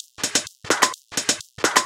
Index of /VEE/VEE Electro Loops 128 BPM
VEE Electro Loop 182.wav